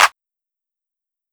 Clap (Yikes).wav